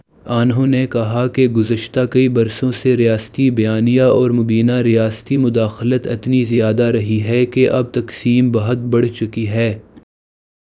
deepfake_detection_dataset_urdu / Spoofed_TTS /Speaker_06 /138.wav